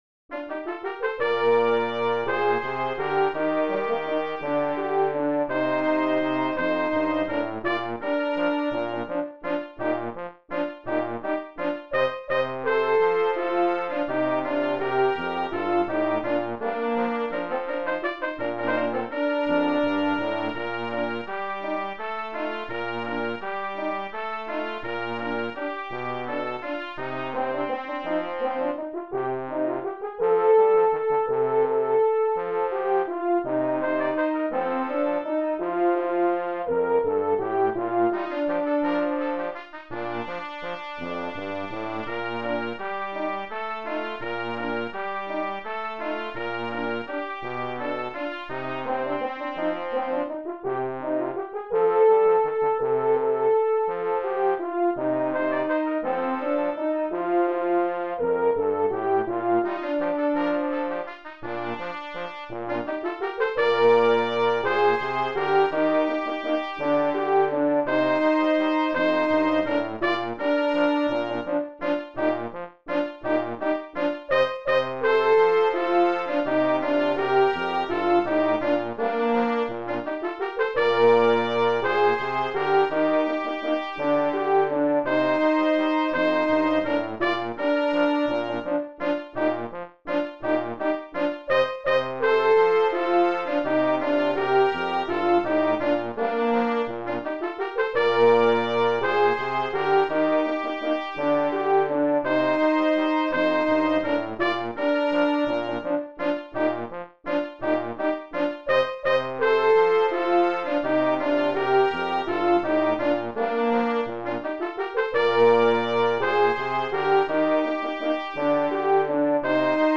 Brass Trio
traditional folk song
(in Bb)